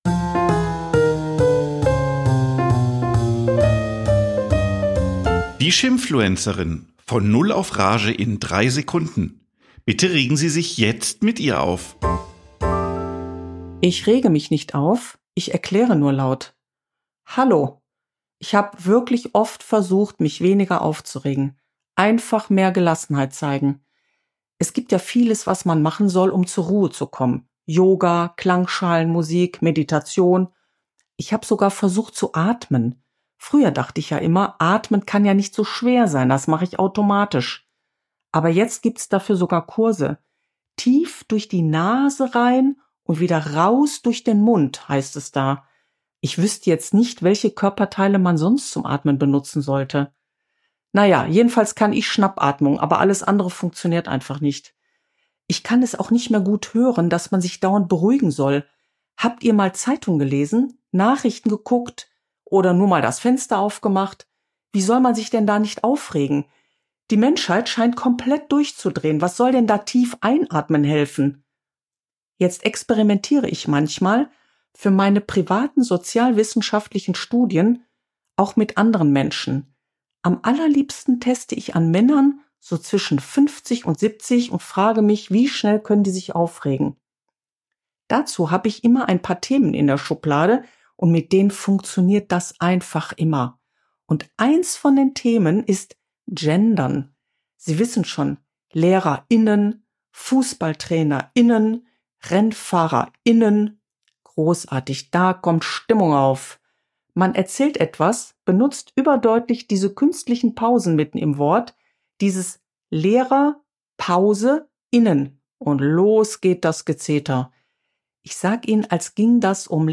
Eine Frau regt sich auf